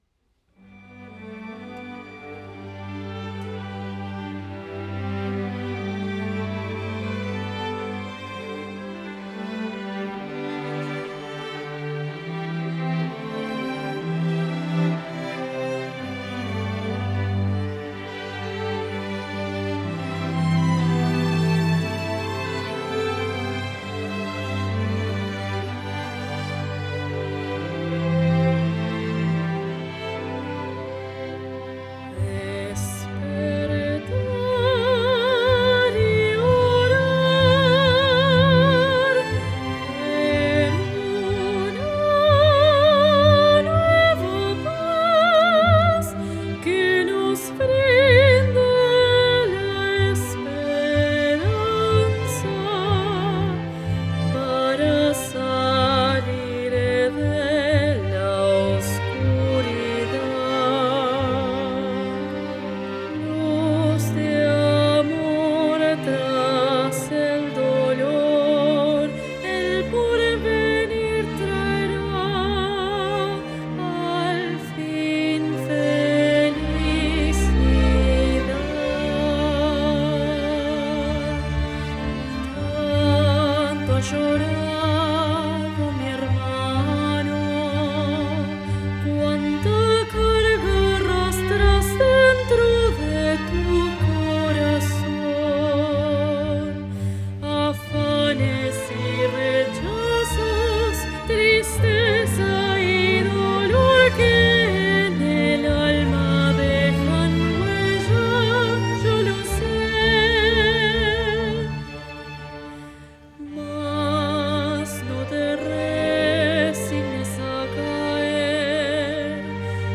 ·        Mood: contemplative, lyrical
Strings, Voice soloist (soprano or tenor) - SPANISH
OPERATIC VERSION: